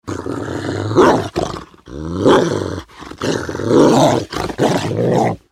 جلوه های صوتی
دانلود صدای سگ 10 از ساعد نیوز با لینک مستقیم و کیفیت بالا
برچسب: دانلود آهنگ های افکت صوتی انسان و موجودات زنده دانلود آلبوم صدای انواع سگ از افکت صوتی انسان و موجودات زنده